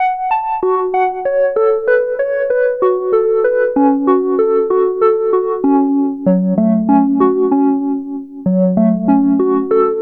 Track 14 - Bells.wav